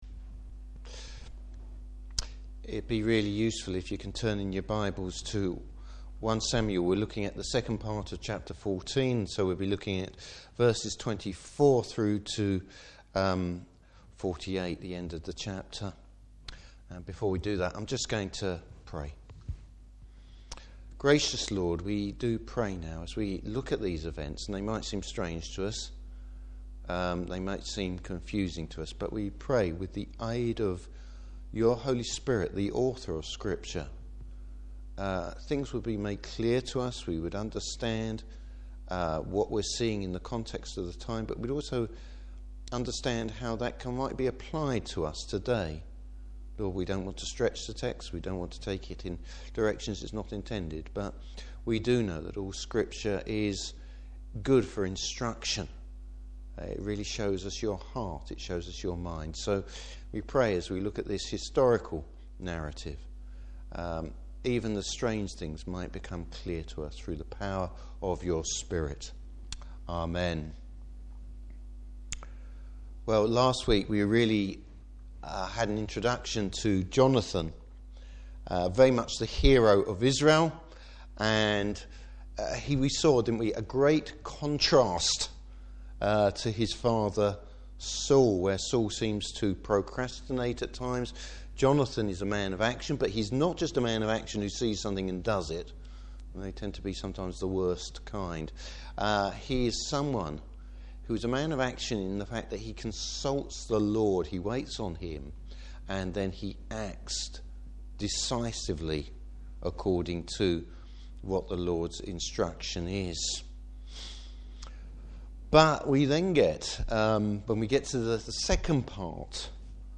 Service Type: Evening Service Saul’s dumb vow! Topics: Religion rather than relationship.